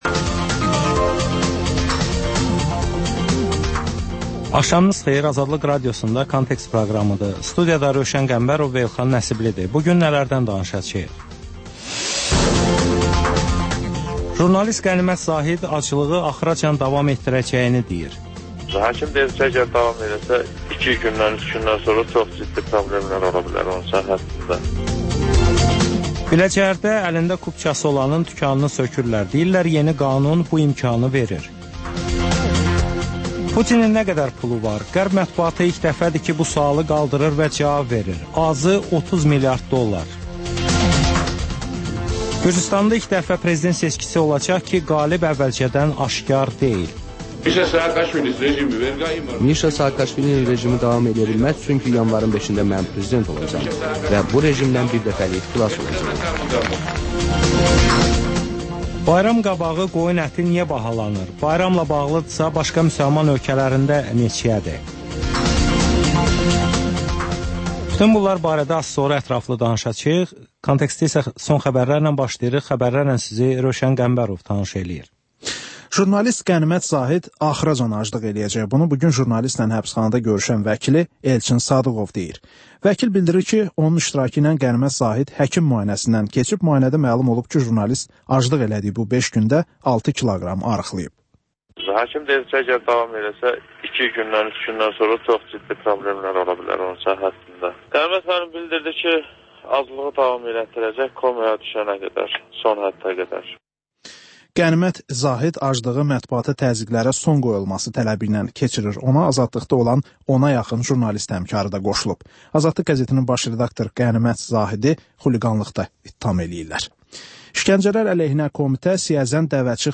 Xəbərlər, müsahibələr, hadisələrin müzakirəsi, təhlillər, sonra QAFQAZ QOVŞAĞI rubrikası: «Azadlıq» Radiosunun Azərbaycan, Ermənistan və Gürcüstan redaksiyalarının müştərək layihəsi